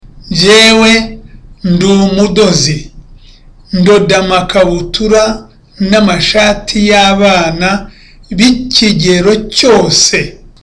(With a low tone.)